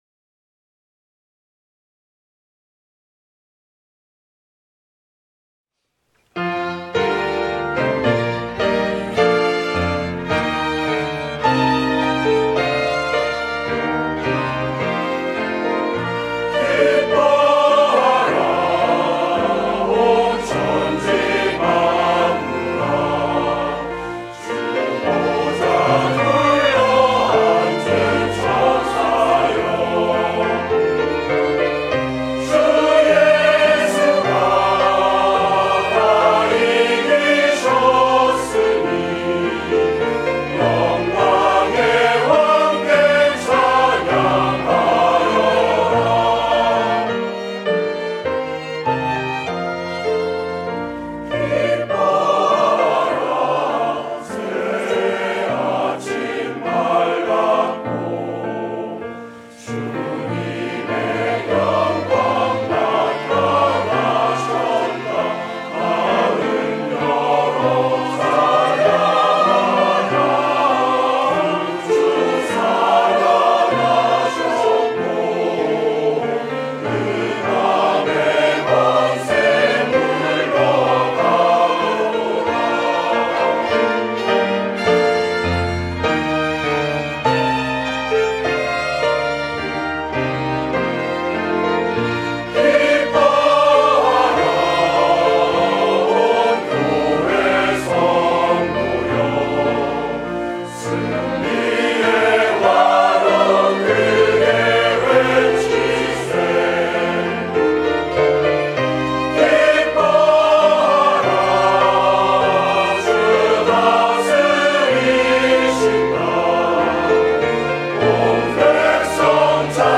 부활절 찬송